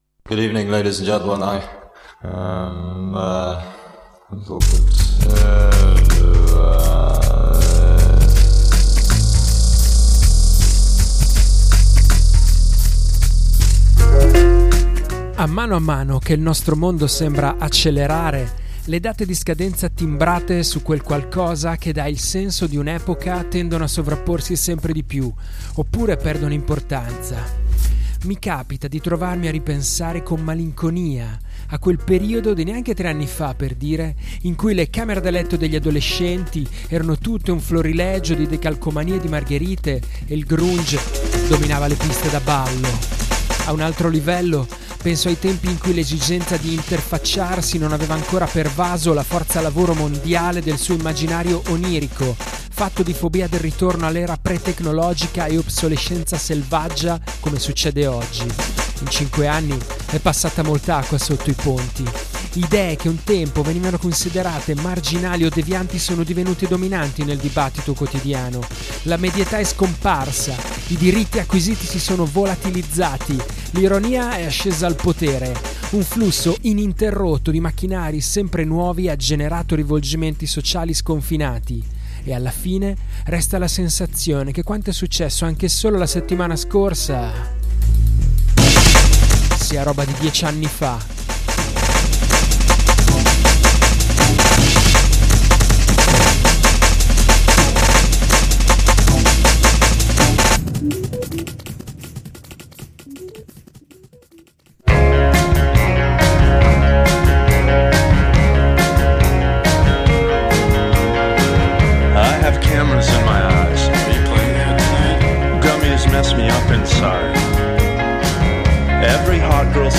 Novità discografiche indiepop, indie rock, shoegaze, post-punk, lo-fi e twee, in onda ogni settimana da Bologna su NEU Radio